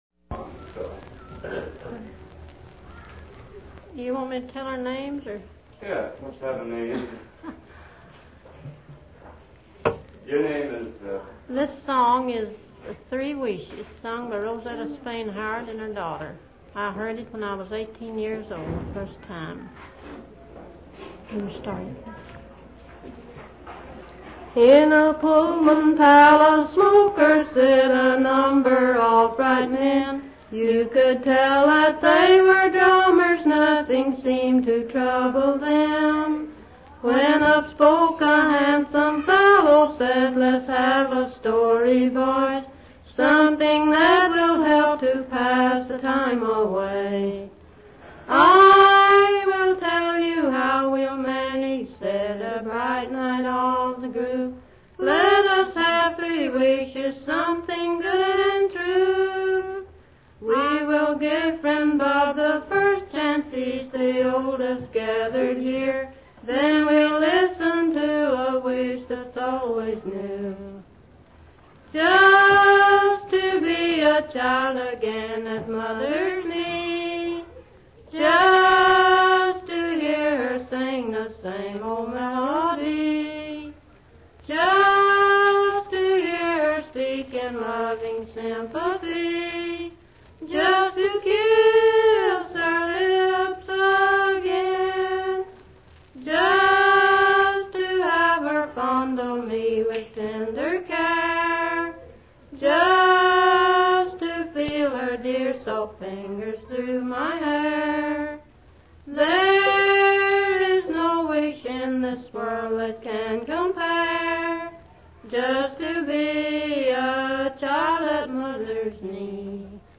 Voices from the Dust Bowl Camp
multi-format ethnographic field collection documenting the everyday life of residents of Farm Security Administration (FSA) migrant work camps in central California in 1940 and 1941.